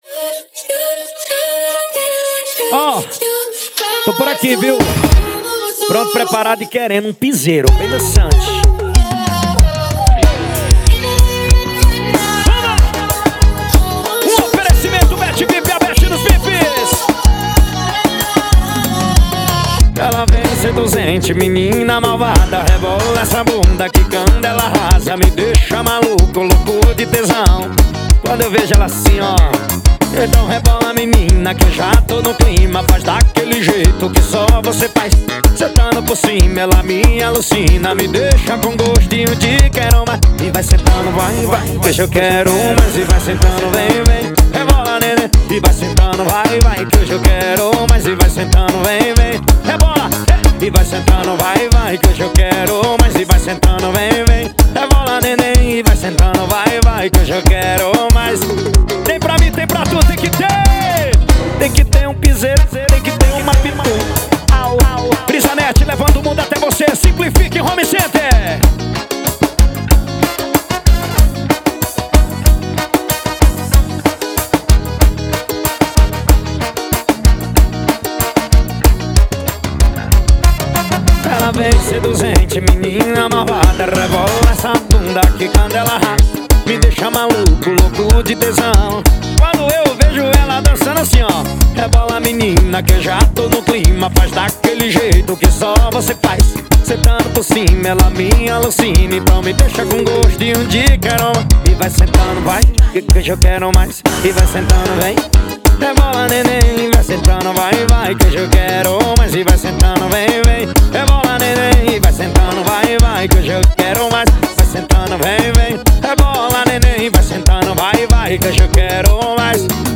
2024-02-14 22:38:23 Gênero: Forró Views